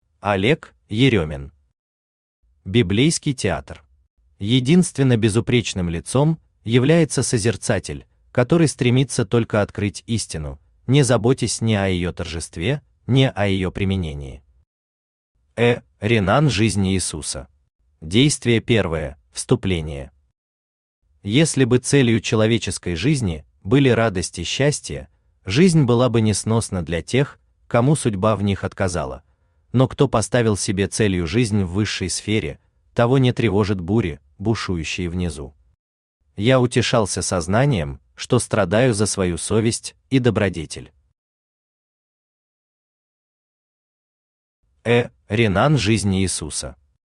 Аудиокнига Библейский театр | Библиотека аудиокниг
Aудиокнига Библейский театр Автор Олег Васильевич Еремин Читает аудиокнигу Авточтец ЛитРес.